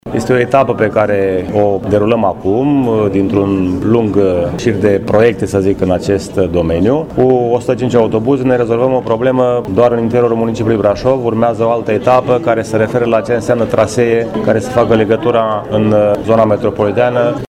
La rândul său, primarul Brașovului, George Scripcaru, a spus că această achiziție este un prim pas în derularea contractului cu firma din Turcia: